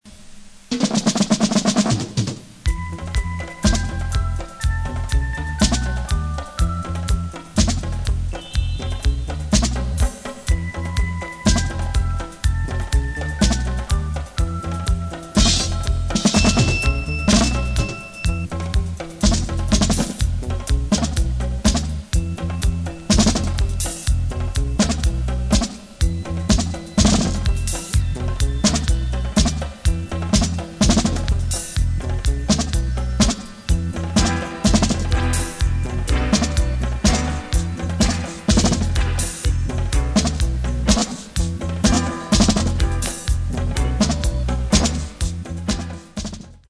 Recorded: Ariwa Studio